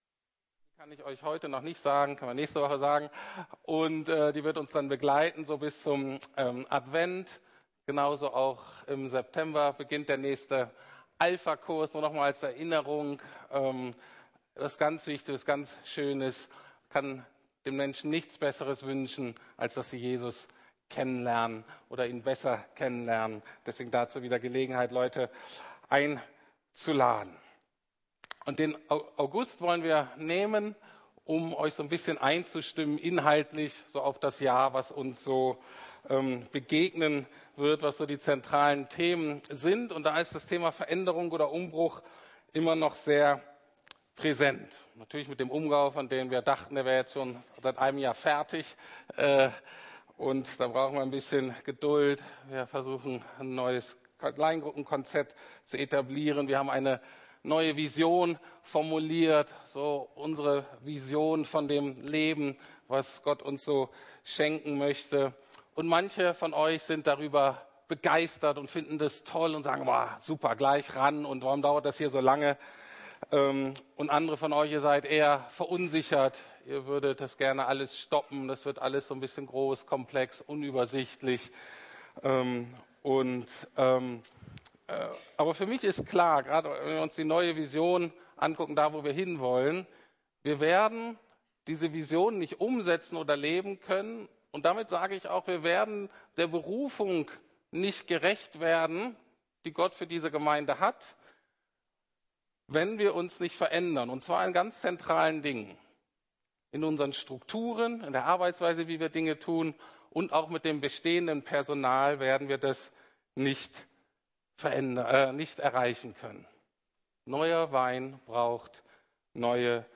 Neuer Wein braucht neue Schläuche ~ Predigten der LUKAS GEMEINDE Podcast